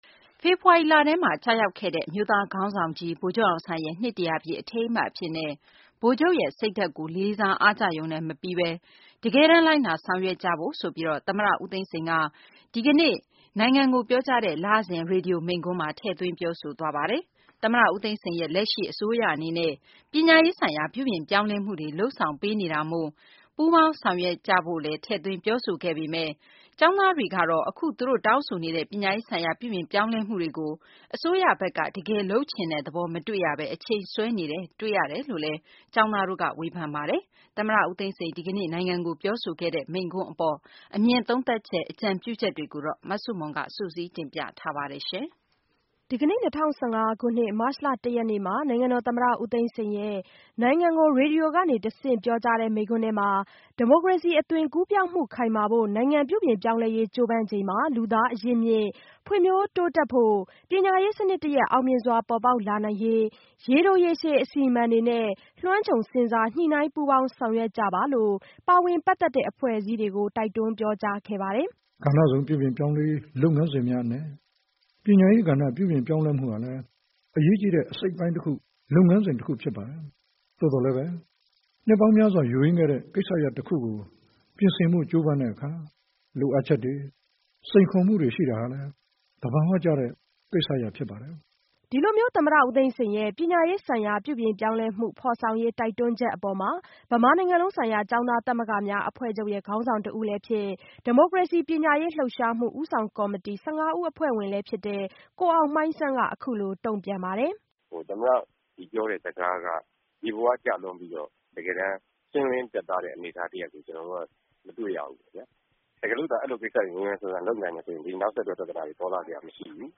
March Pre.TS speech